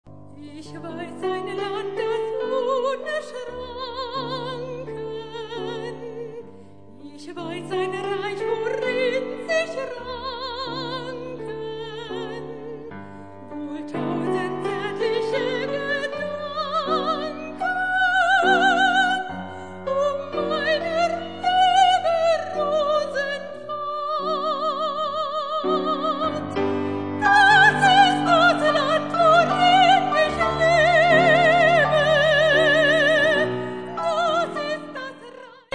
Sopran
Flügel